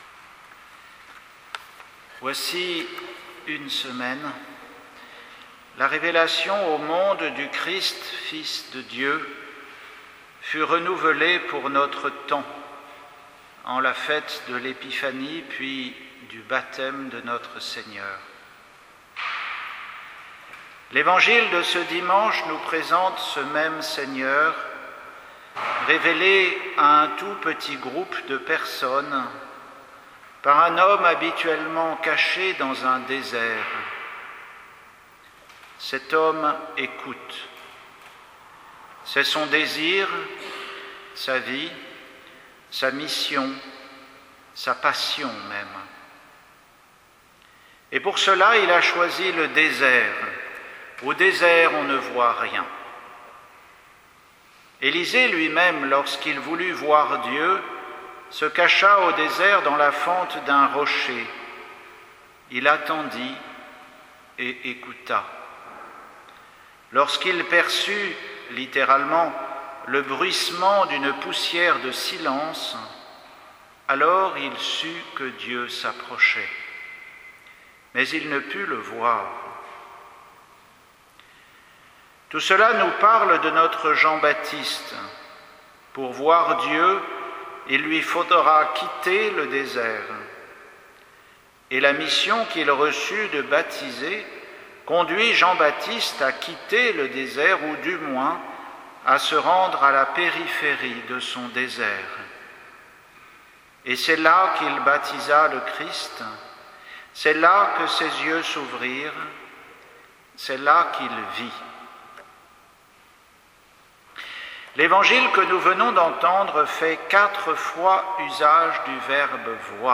Homélie pour le deuxième dimanche du temps ordinaire, à l'abbaye St Michel